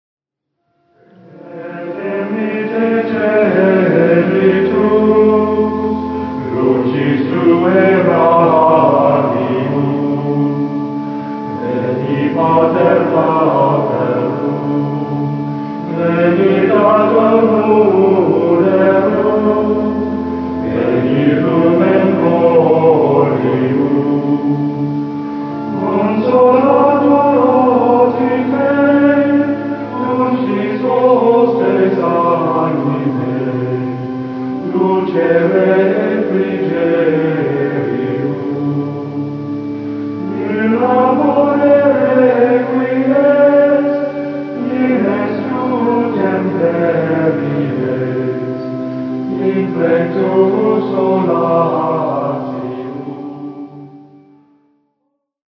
Gregorian Chant Exclusives